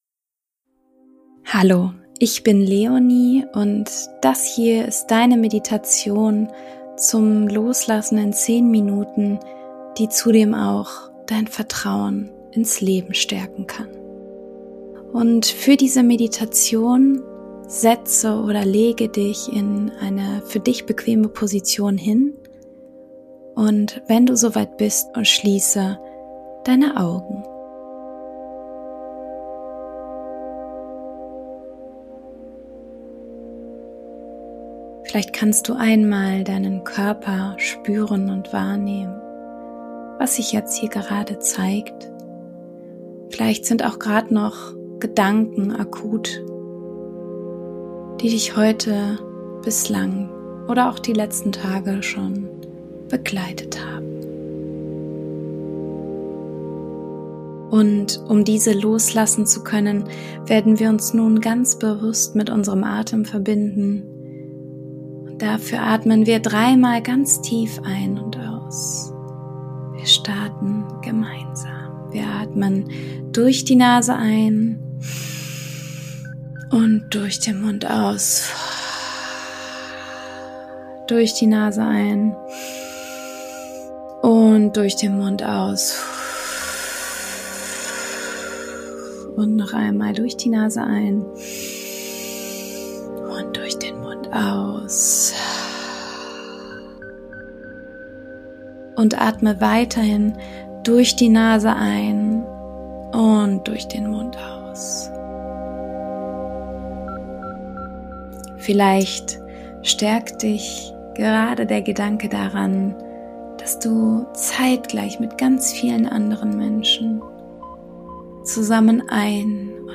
In stürmischen Zeiten Vertrauen ins Leben zu haben, ist gar nicht so einfach. Wenn du dein Vertrauen bewusst erhöhen möchtest und dich danach sehnst, innerlich einfach mal loszulassen, ist diese Meditation genau das Richtige für dich.